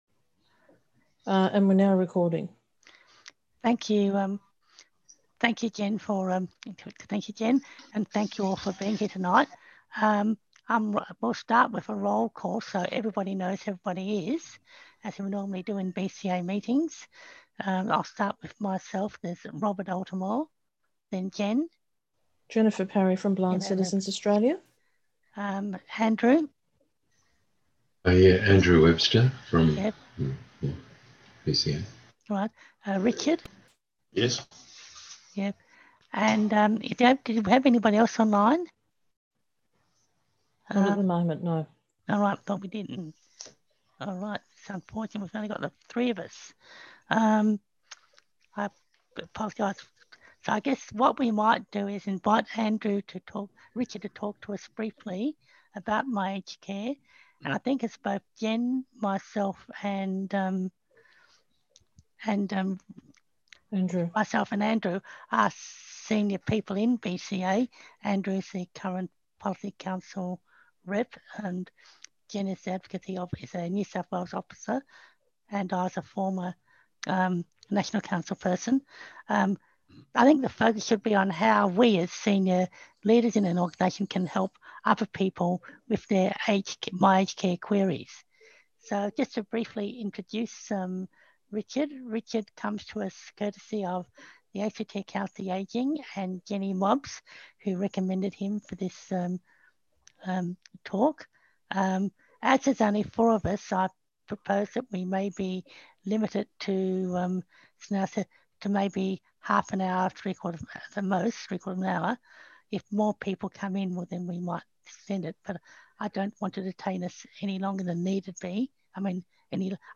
2020_ACT_My_Aged_Care_Forum.m4a